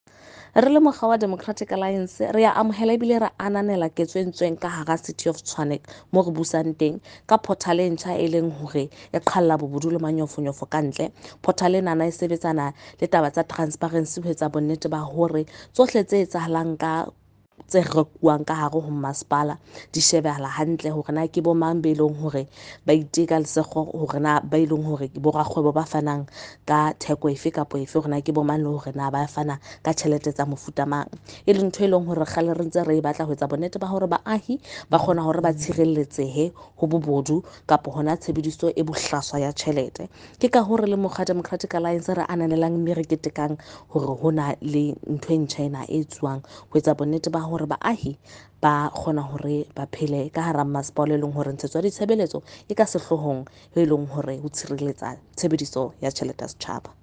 Note to editors: Please find attached soundbites in
Sesotho by Karabo Khakhau MP